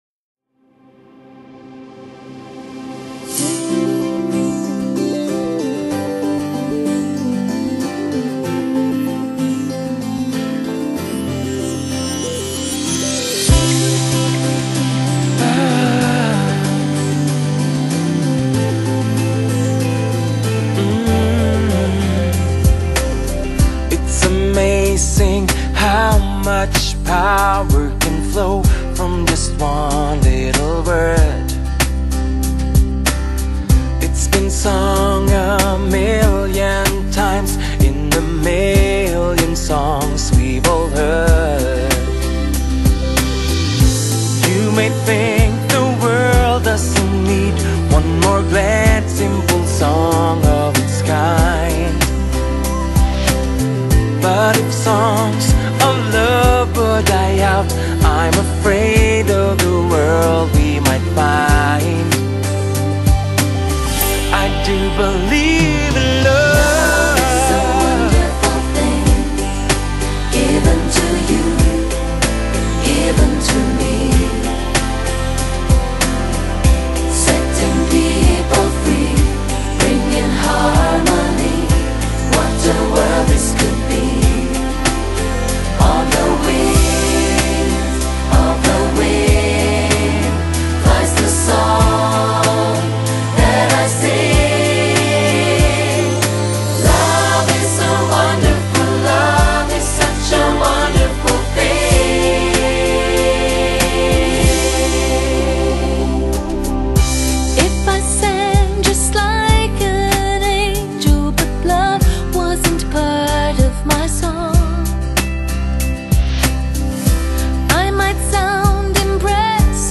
從頭到尾彌漫著濃厚的黑人靈歌氣息，又佐以北歐人擅長的福音曲調和悅耳動聽的流行編曲